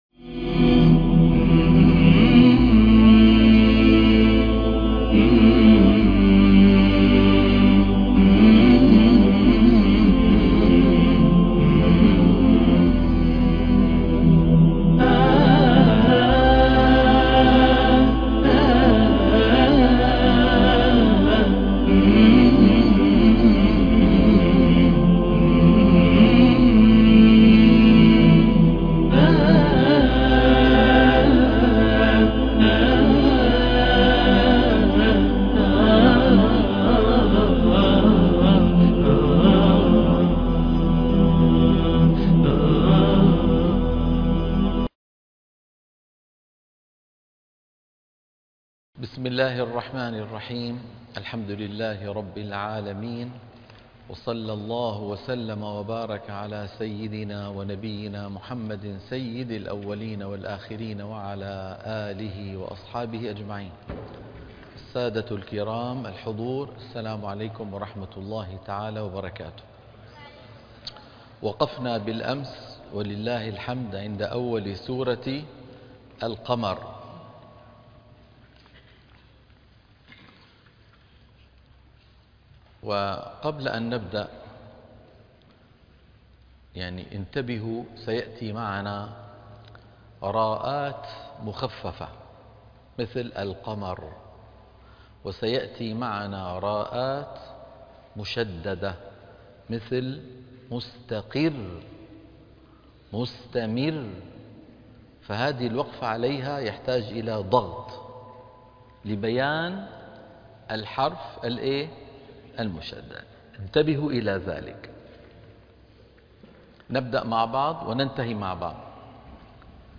تلقين من بداية سورة القمر إلى الآية 17